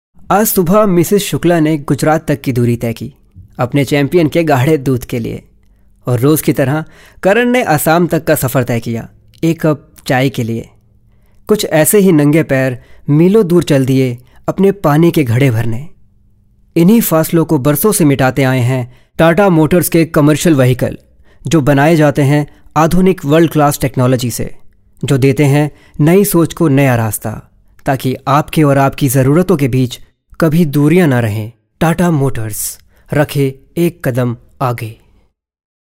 a professional voice over artist with good command over hindi , english and punjabi
Sprechprobe: Industrie (Muttersprache):